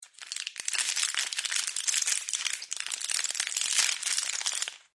На этой странице собрана коллекция звуков жевания жвачки.
Шепот лопающегося пузыря жвачки